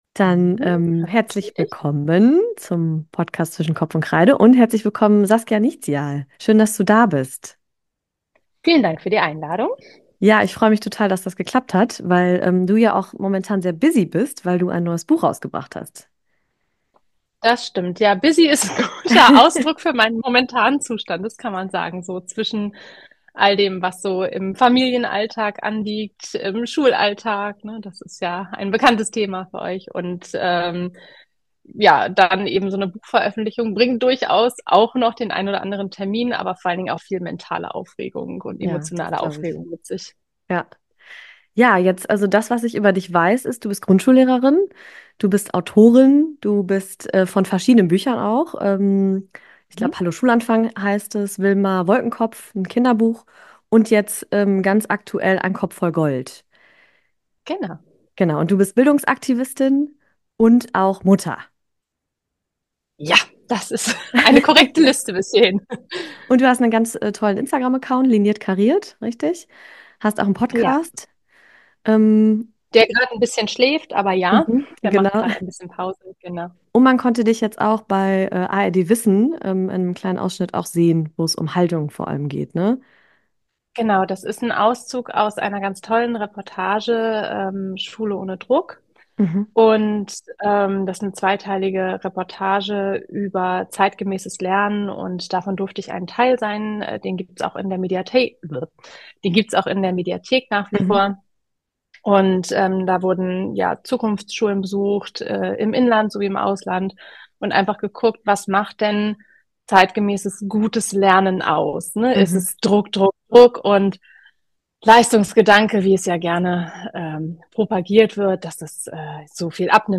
Ein inspirierendes Gespräch über Vielfalt, Inklusion und das Aufbrechen traditioneller Denkmuster.